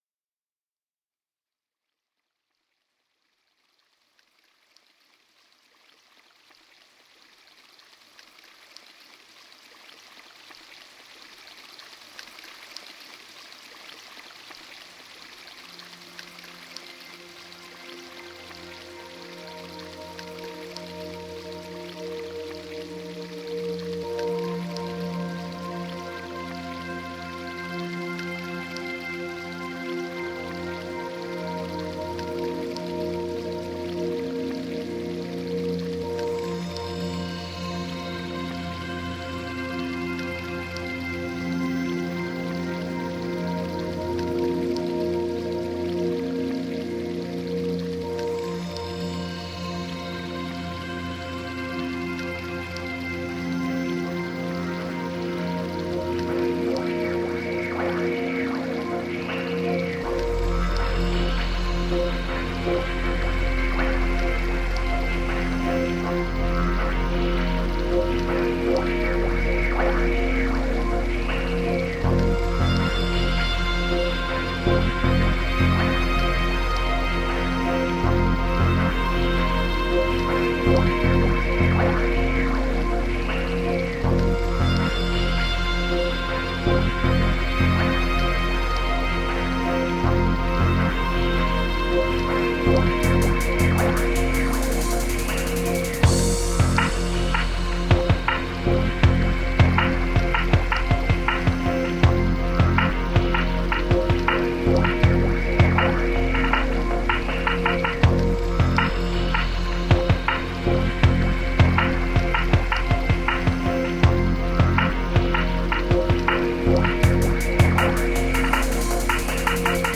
A summer cocktail of chill out, electronica and ambient.
Tagged as: Electronica, Other, Chillout